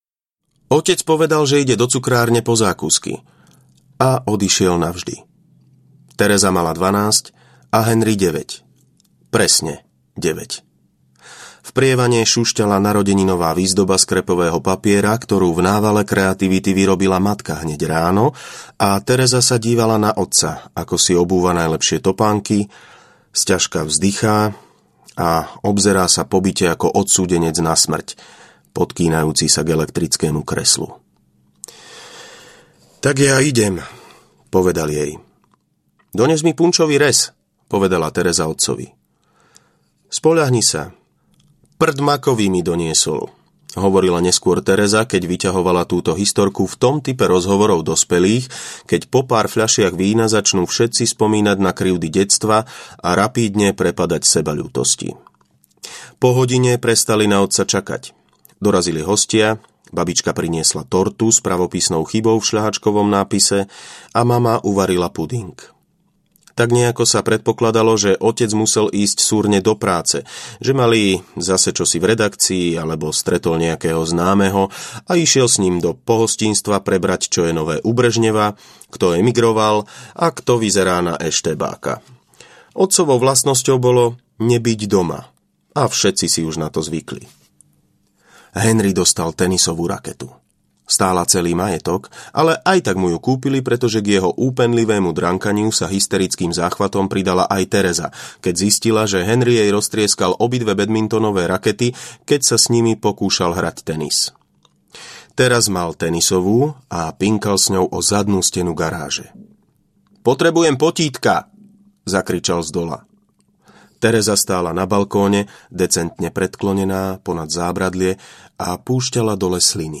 Mexická vlna audiokniha
Ukázka z knihy